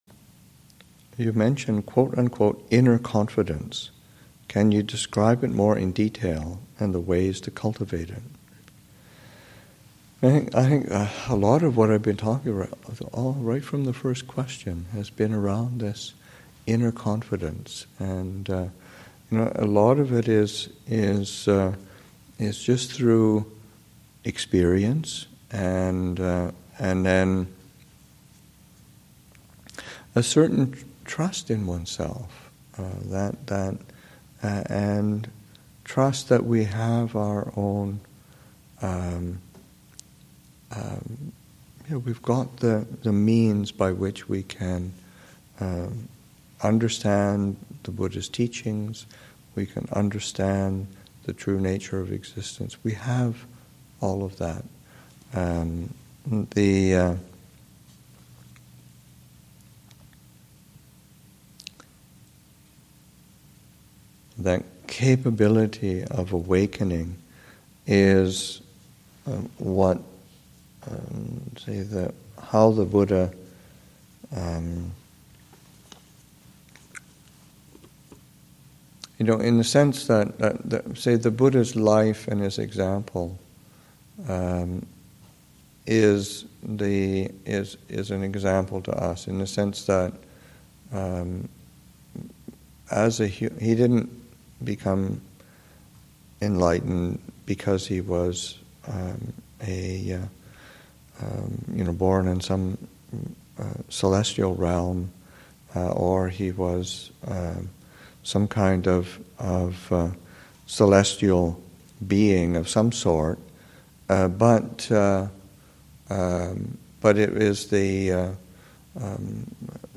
2014 Thanksgiving Monastic Retreat, Session 3 – Nov. 24, 2014